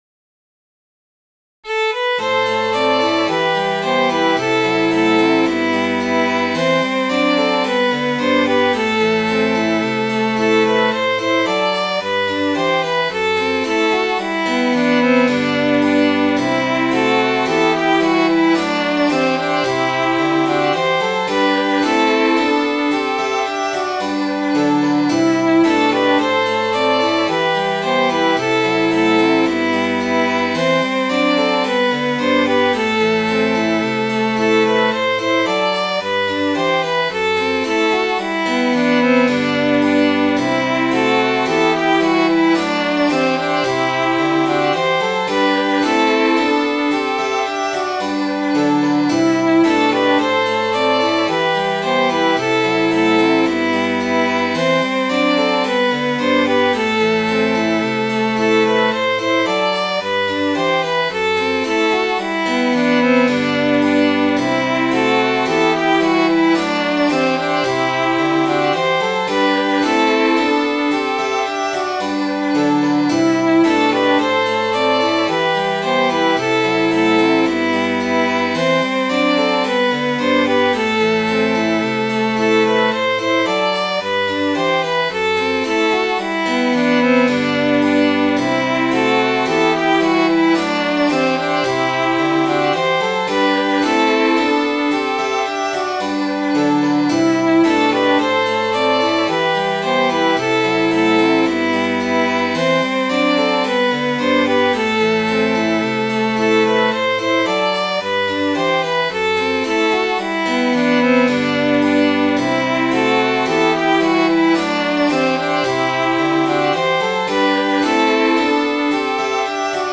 Midi File, Lyrics and Information to The True Lover's Farewell